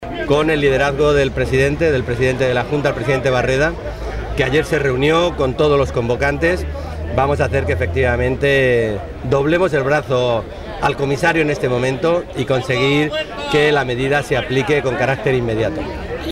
El dirigente socialista hacía estas declaraciones minutos antes de que comenzara la concentración convocada en Toledo por organizaciones agrarias para pedir a la Unión Europea la puesta en marcha del almacenamiento privado de aceite de oliva.
Cortes de audio de la rueda de prensa